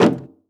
MetalSteps_03.wav